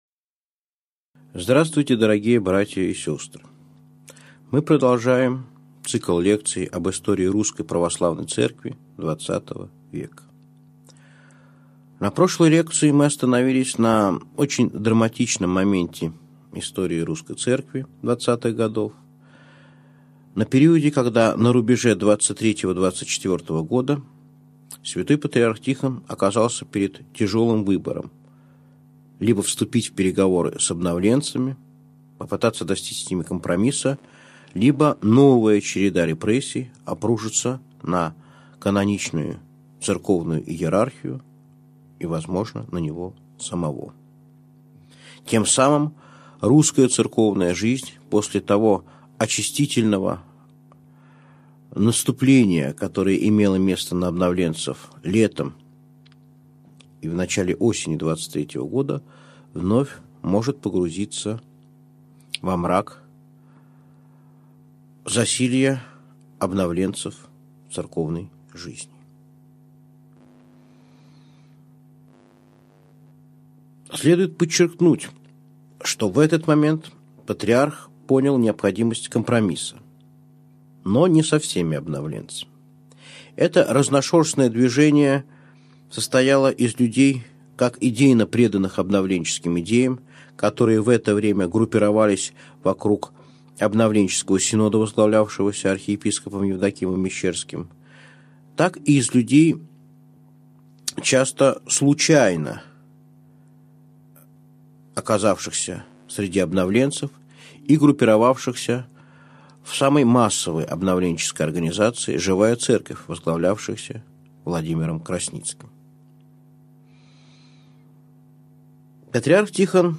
Аудиокнига Лекция 9. «Победа над обновленцами» | Библиотека аудиокниг